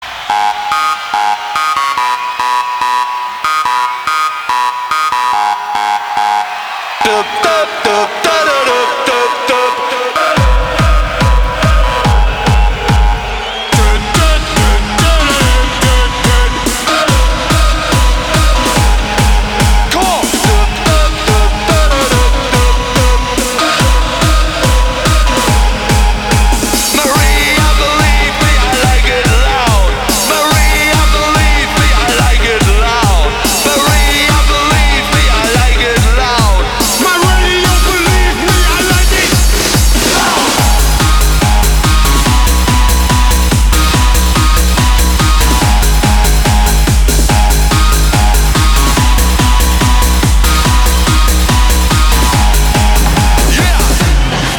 • Качество: 251, Stereo
веселые
dance